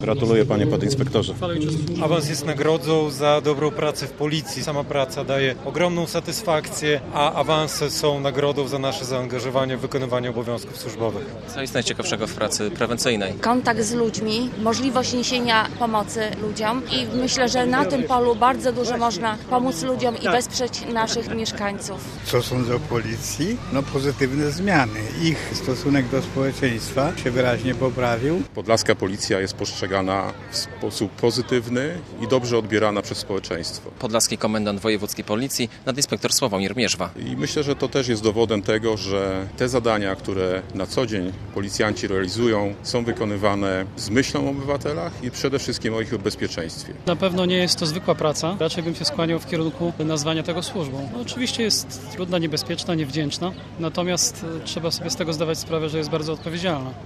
Święto policji - relacja
Uroczystość odbyła się w środę (17.07) w samym centrum Białegostoku - przy pomniku marszałka Józefa Piłsudskiego.